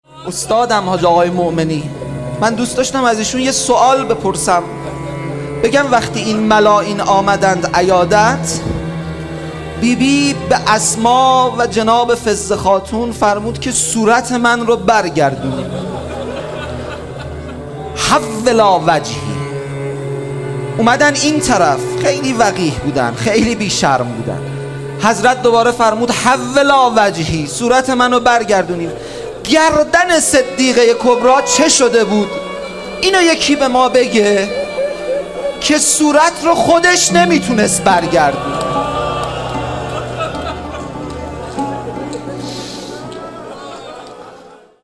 ایام فاطمیه 1441